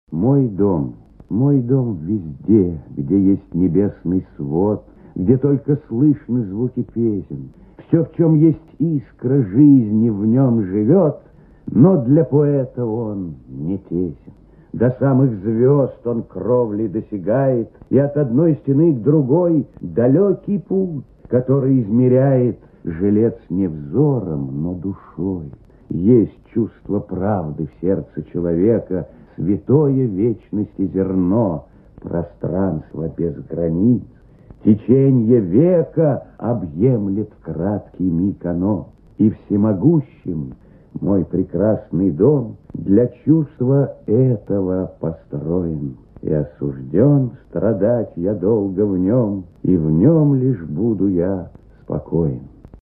Прослушивание аудиозаписи стихотворения «Мой дом» с сайта «Старое радио». Исполнитель О. Ефремов.